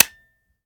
Home gmod sound weapons papa320
weap_papa320_fire_first_plr_01.ogg